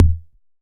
RDM_TapeA_SR88-Kick.wav